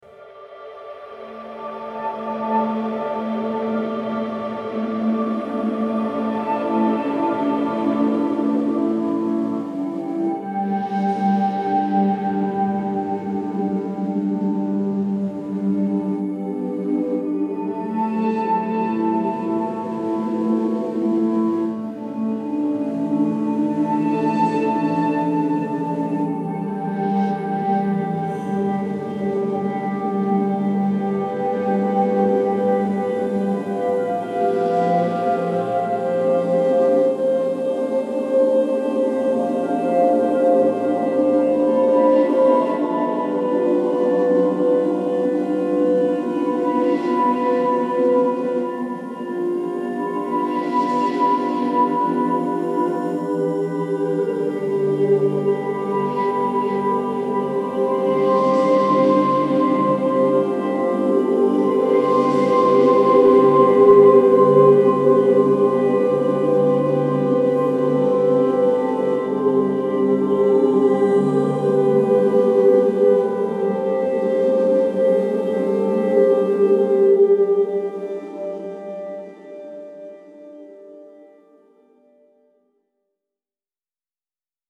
Genre : Bandes originales de films